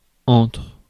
Ääntäminen
UK : IPA : /bɪˈtwiːn/